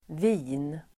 Uttal: [vi:n]